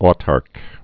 tärk)